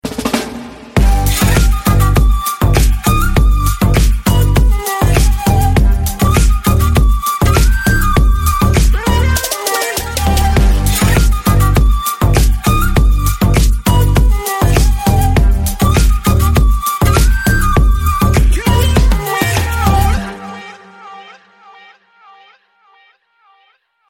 Клубные Рингтоны » # Восточные Рингтоны
Танцевальные Рингтоны